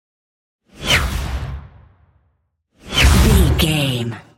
Airy whoosh pass by fast
Sound Effects
futuristic
pass by
sci fi